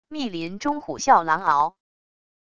密林中虎啸狼嗷wav音频